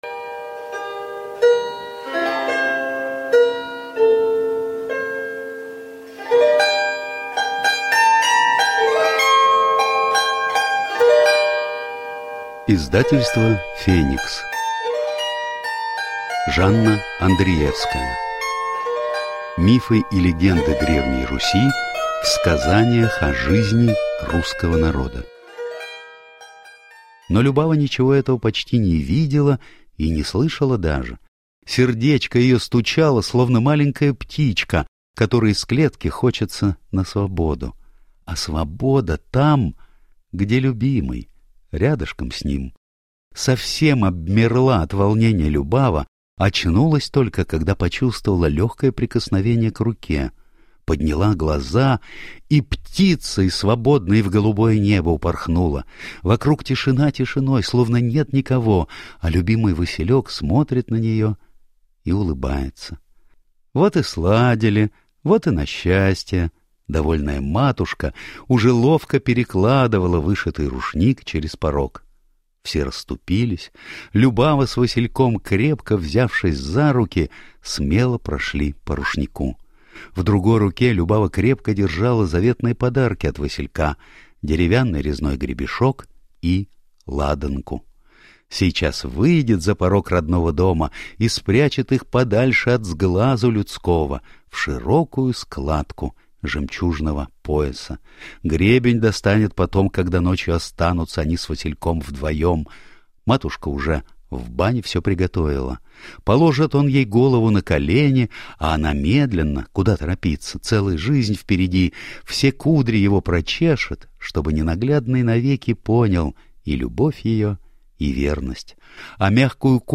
Аудиокнига Мифы и легенды Древней Руси в сказаниях о жизни русского народа | Библиотека аудиокниг